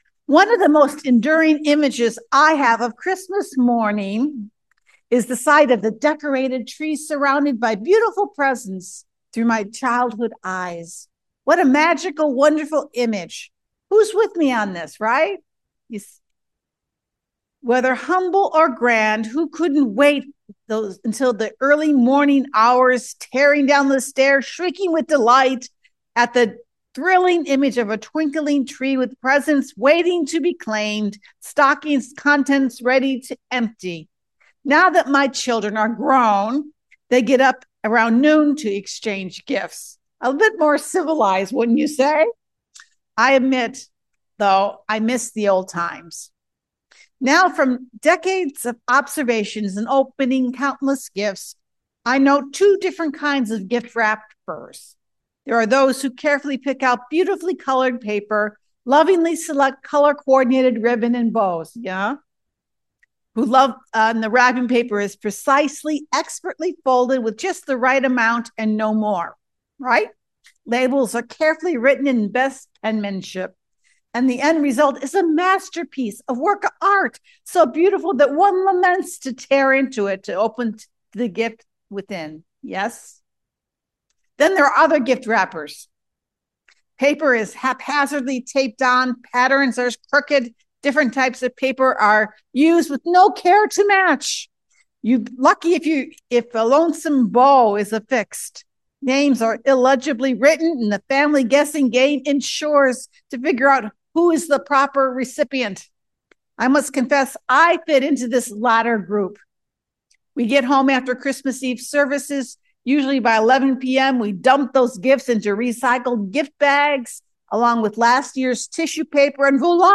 Service Type: Christmas